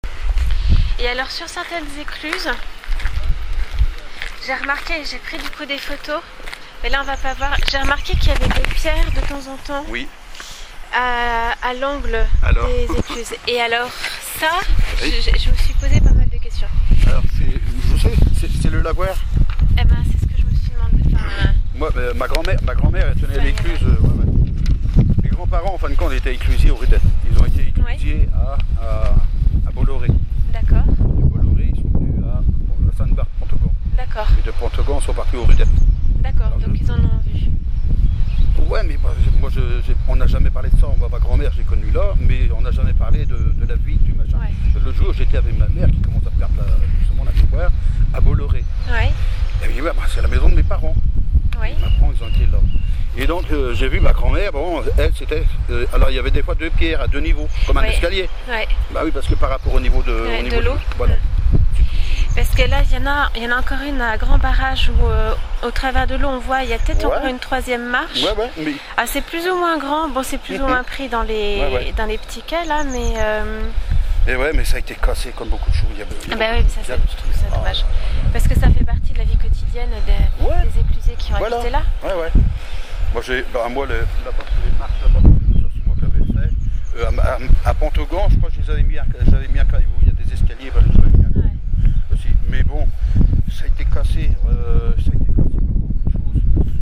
Lien vers l'extrait sonore ; Lien vers la transcription écrite de l'entretien